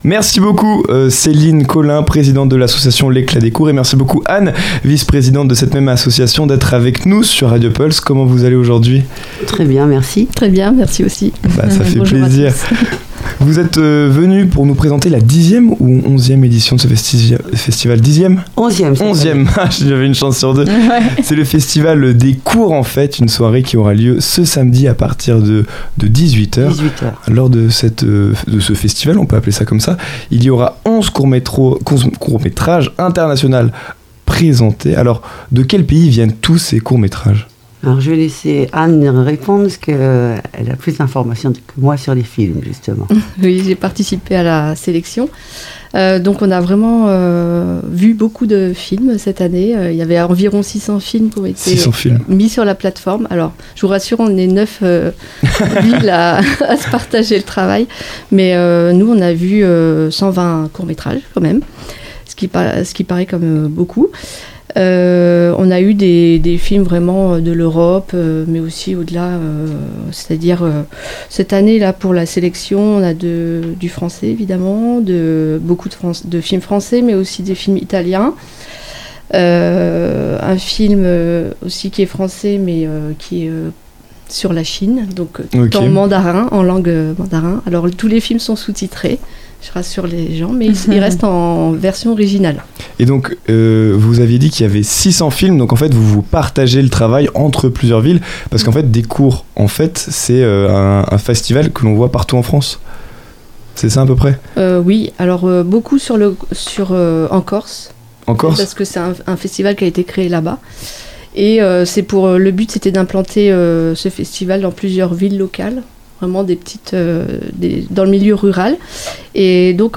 nous avons eu le plaisir d'accueillir dans les locaux de RadioPulse, en direct